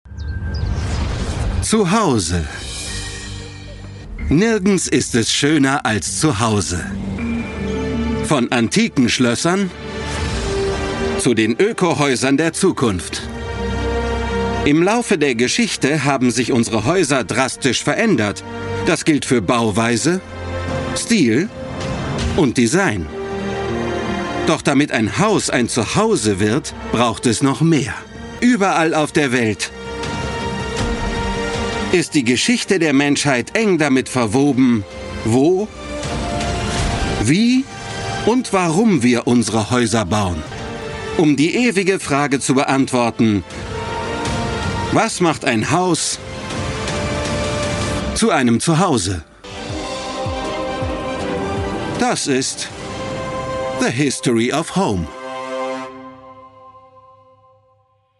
Mittel plus (35-65)
Doku